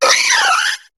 Cri de Férosinge dans Pokémon HOME.